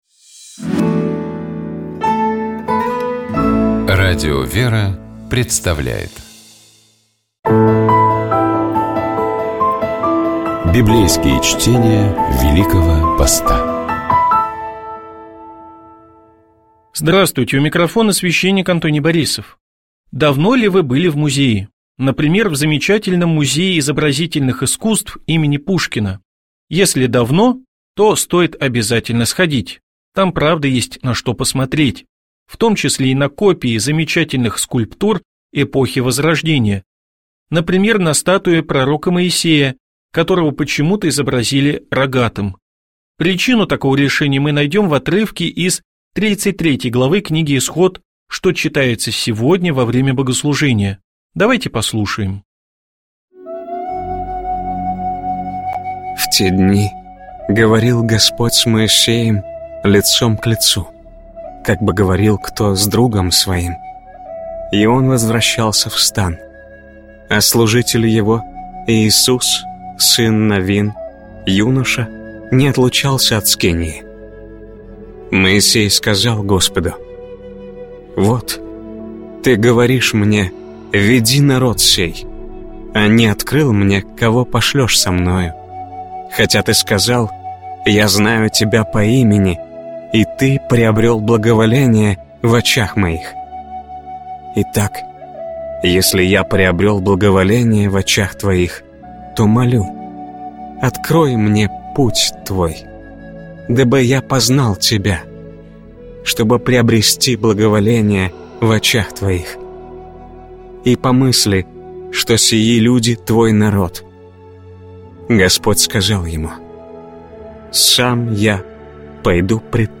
Библейские чтения
Читает и комментирует